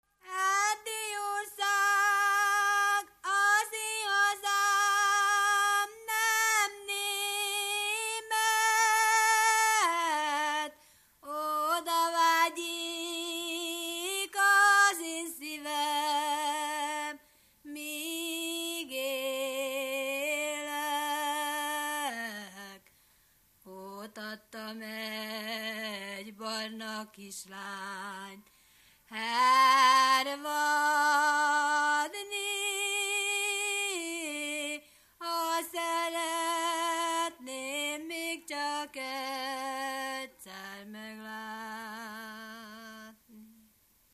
Erdély - Kolozs vm. - Méra
ének
Stílus: 6. Duda-kanász mulattató stílus
Kadencia: 8 (4) 4 1